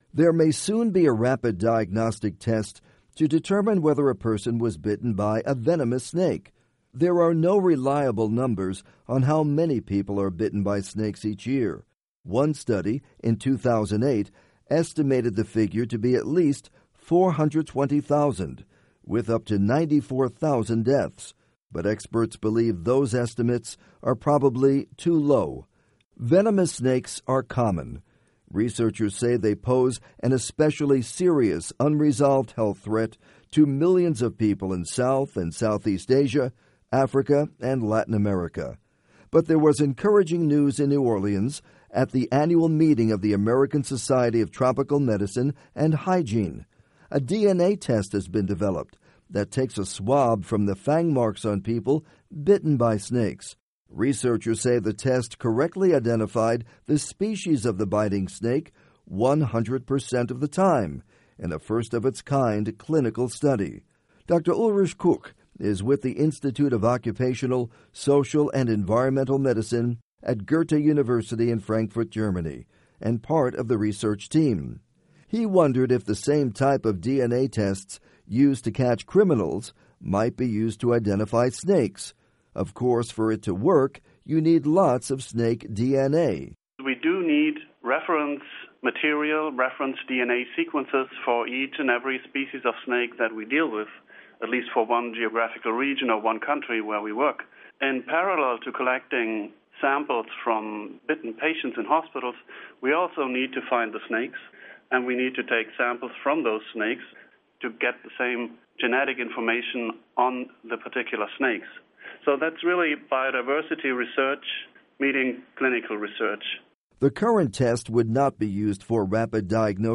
report on DNA snake bite test